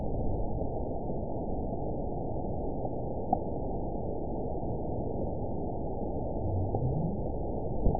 event 922794 date 04/06/25 time 00:35:09 GMT (2 months, 1 week ago) score 9.56 location TSS-AB02 detected by nrw target species NRW annotations +NRW Spectrogram: Frequency (kHz) vs. Time (s) audio not available .wav